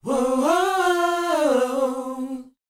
WHOA C A.wav